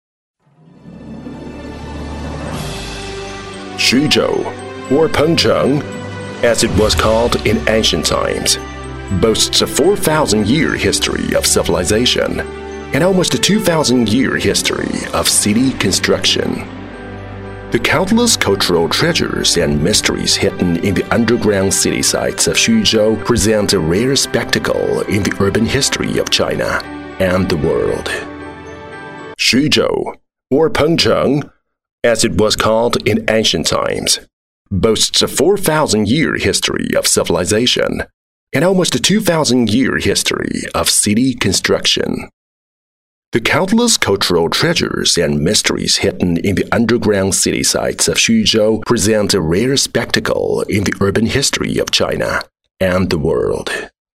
博物馆讲解